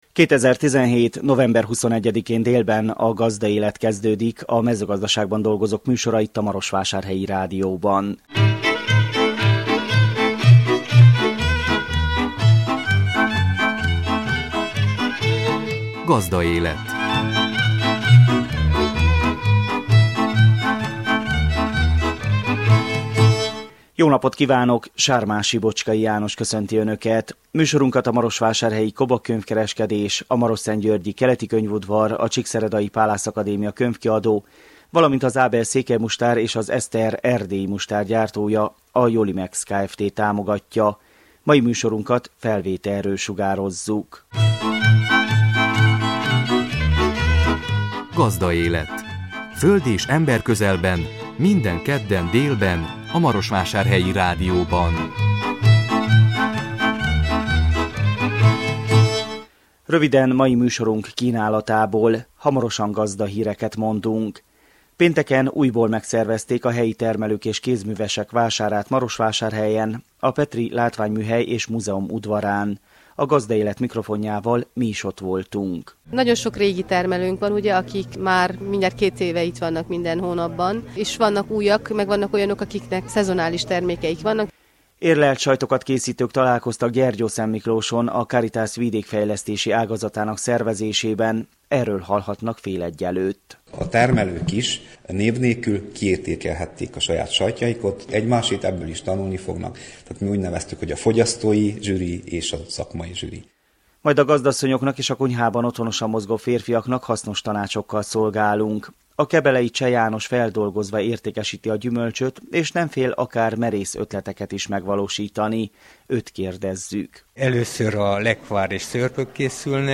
A Gazdaélet mikrofonjával mi is ott voltunk. Érlelt sajtot készítők találkoztak Gyergyószentmiklóson a Caritas Vidékfejlesztési ágazatának szervezésében.